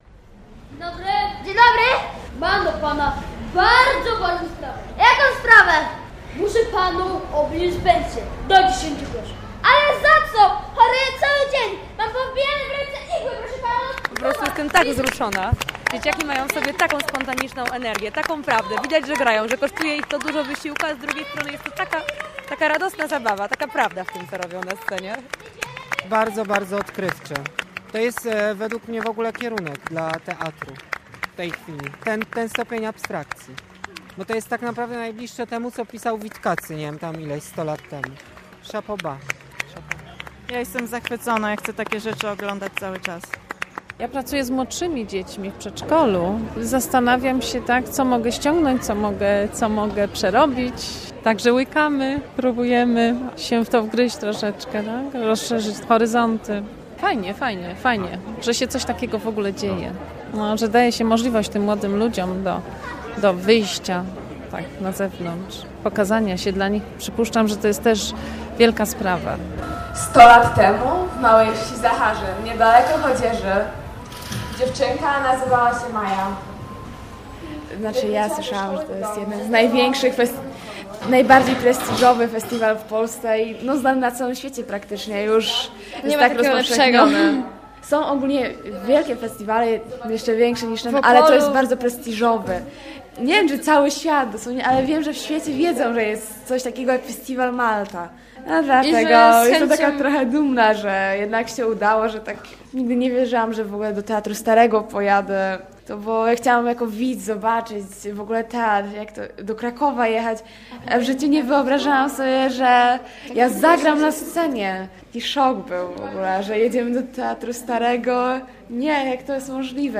"Lepiej tam nie idź" - reportaż